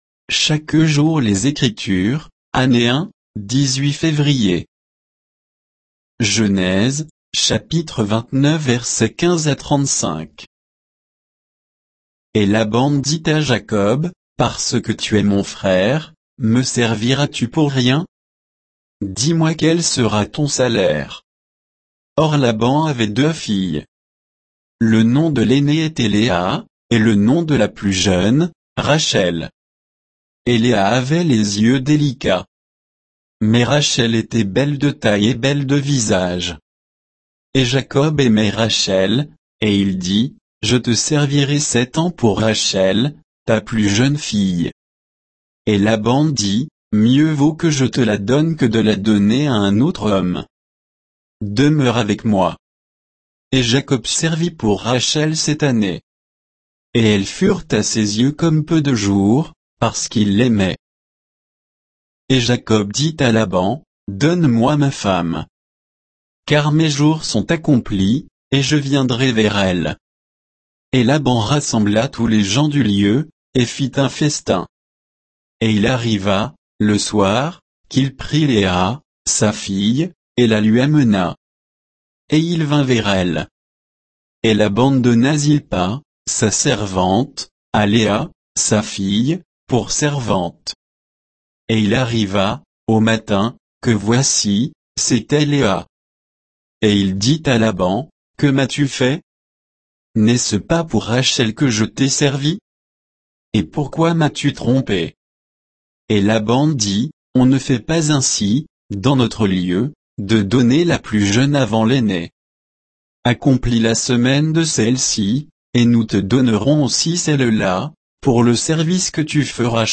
Méditation quoditienne de Chaque jour les Écritures sur Genèse 29, 15 à 35